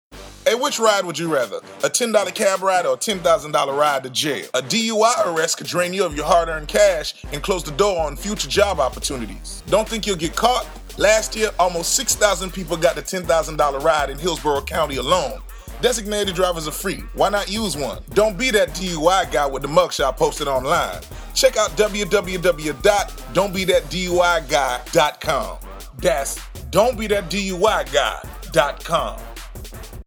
Don't Be That DUI Guy 30 second radio ad: